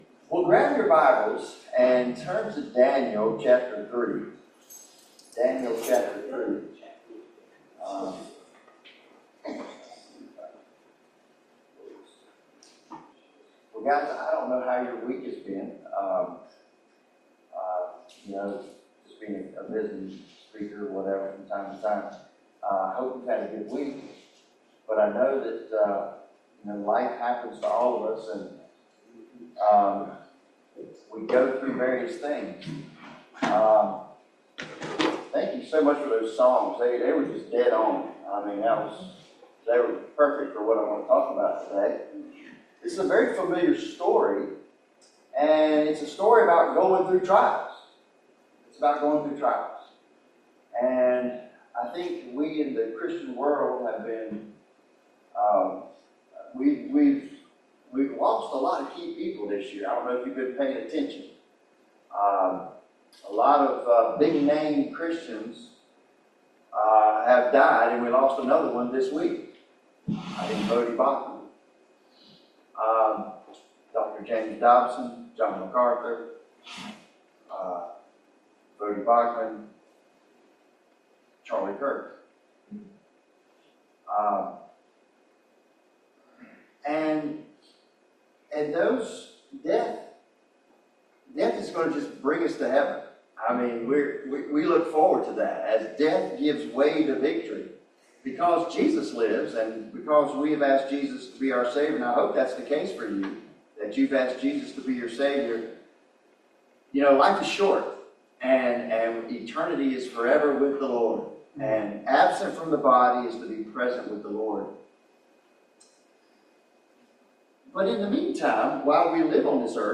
Daniel 3 Service Type: Family Bible Hour Trials will come.